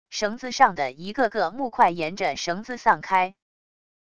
绳子上的一个个木块沿着绳子散开wav音频